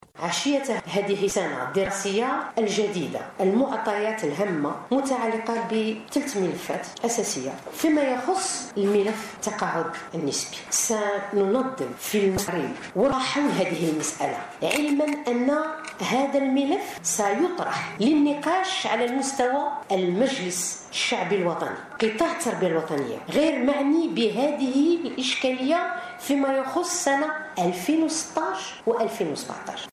وزيرة التربية الوطنية تتحدث عن إدانة المتورطين في الغش في بكالوريا 2016